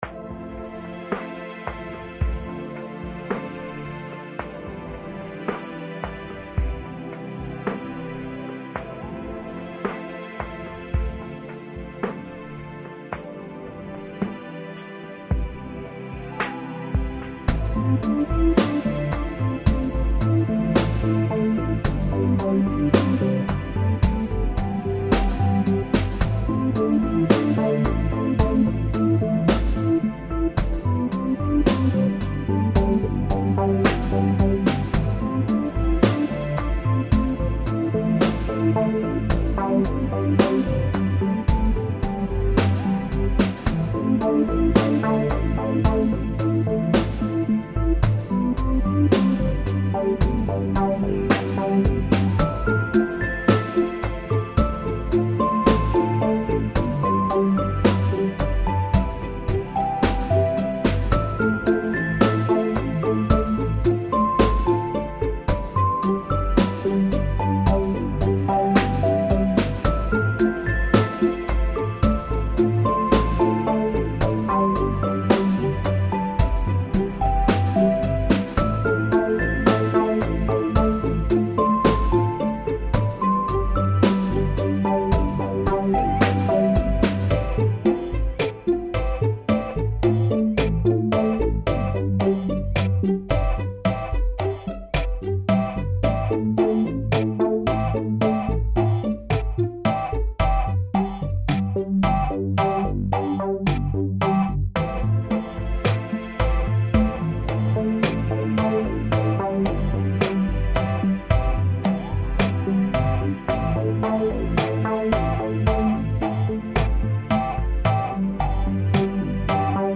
lounge - club - cool